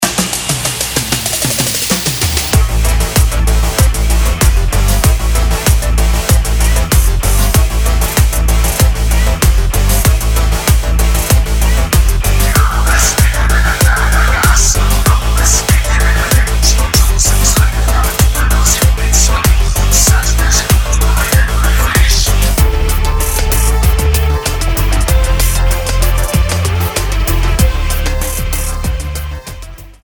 シンセ・ディスコ/エレクトロ度が格段に上昇、よくぞここまで違和感無く手を加えられたな、という仕上がり。